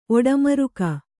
♪ oḍamaruka